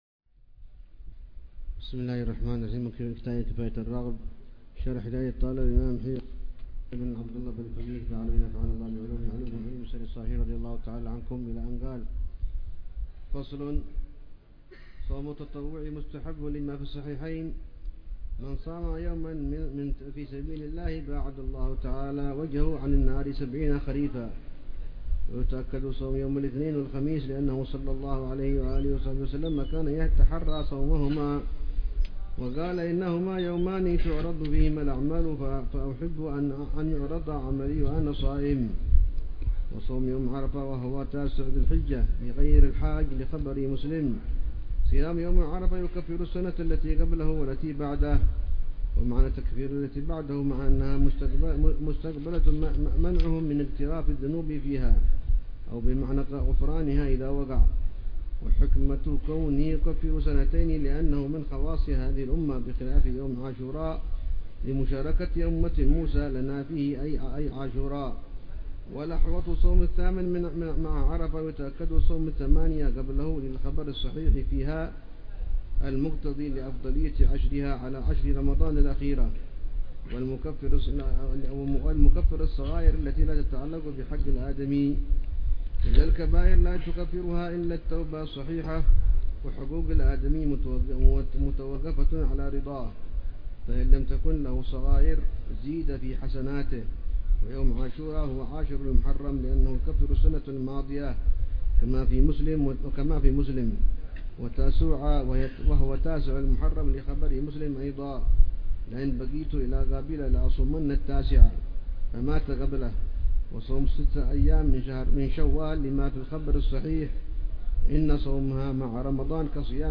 شرح الحبيب عمر بن حفيظ على كتاب كفاية الراغب شرح هداية الطالب إلى معرفة الواجب للإمام العلامة عبد الله بن الحسين بن عبد الله بلفقيه.